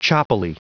Prononciation du mot choppily en anglais (fichier audio)
Prononciation du mot : choppily